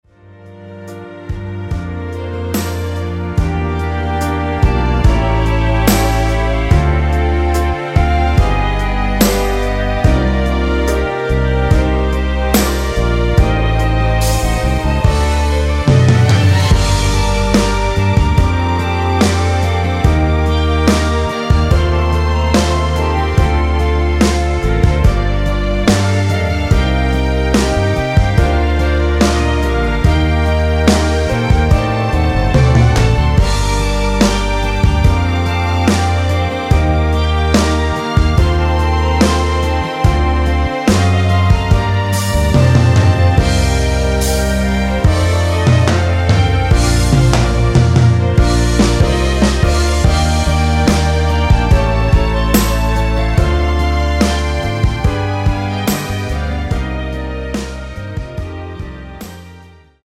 원키에서(+3)올린 멜로디 포함된 MR입니다.
Bb
앞부분30초, 뒷부분30초씩 편집해서 올려 드리고 있습니다.
중간에 음이 끈어지고 다시 나오는 이유는